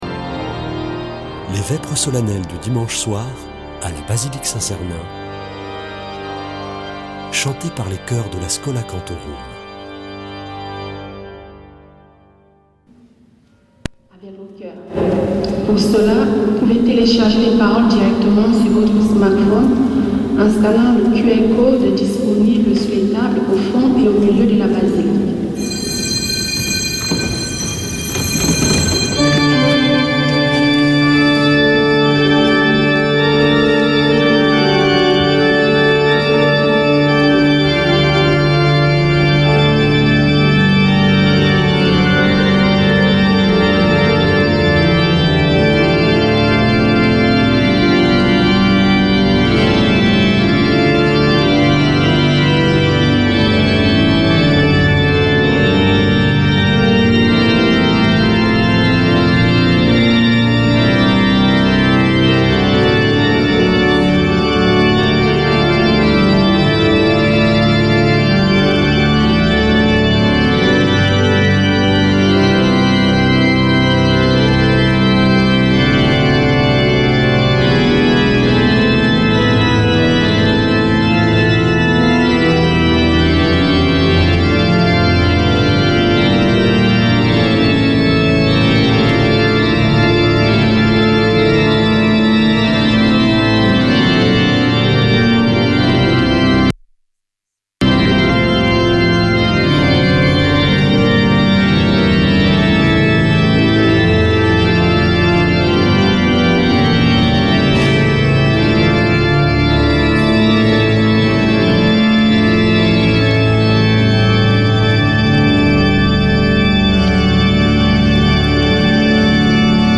Vêpres de Saint Sernin du 12 nov.
Une émission présentée par Schola Saint Sernin Chanteurs